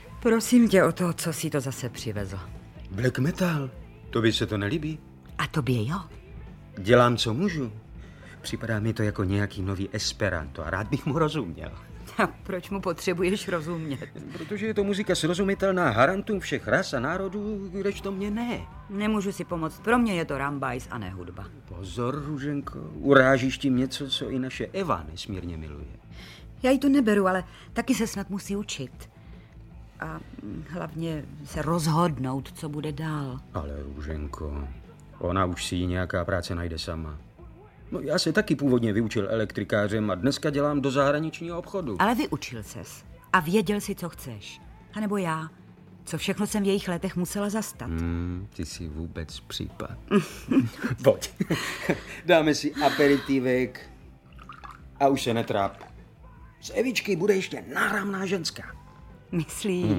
Audiobook
Read: Petr Štěpánek